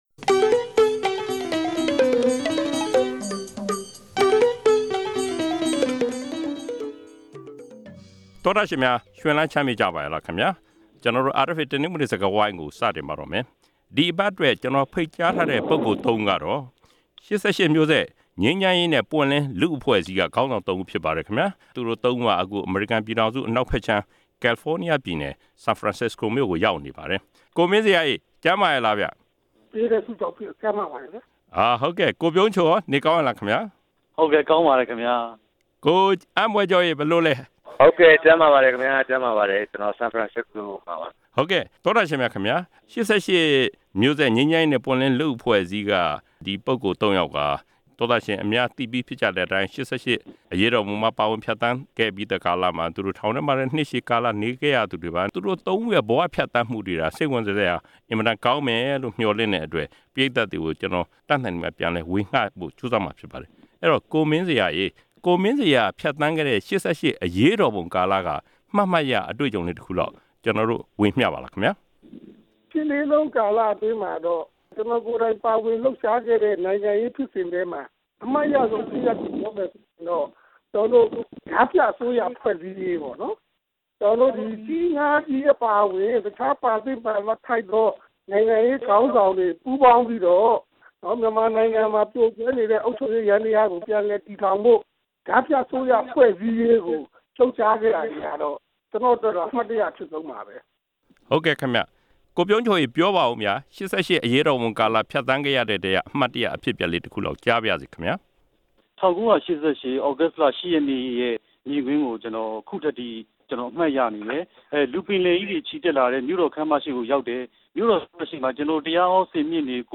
ဒီအပတ် တနင်္ဂနွေစကားဝိုင်းမှာ အမေရိကန်ပြည်ထောင်စုကို ဒီရက်ပိုင်းရောက်ရှိလည်ပတ်နေကြတဲ့ ၈၈ အရေးအခင်း ကျောင်းသားခေါင်းဆောင် ၃ ဦးရဲ့ ဖြတ်သန်းမှု ကိုယ်တွေ့အဖြစ်အပျက်တချို့နဲ့ နိုင်ငံရေးအမြင်တွေကို နားဆင်ကြရမှာဖြစ်ပါတယ်။